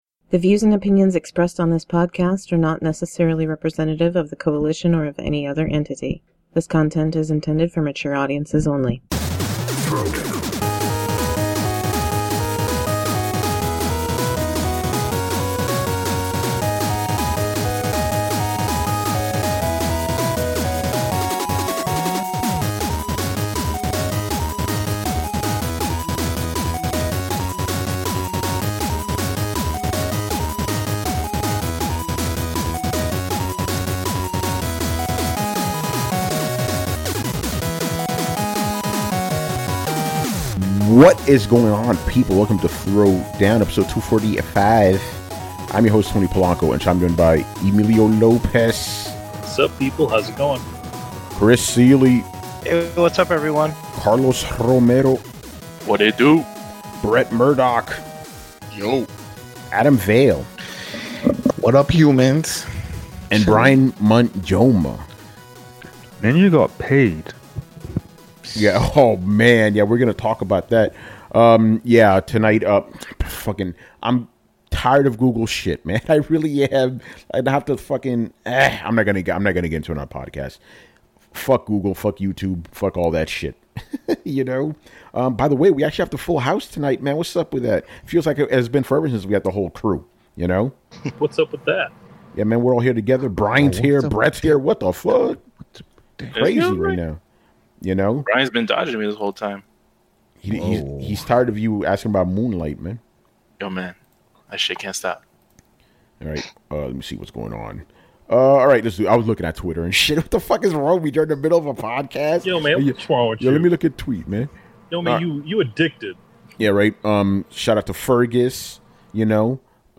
On this podcast/vidcast we discuss the latest video game news and topics in an uncompromising and honest manner.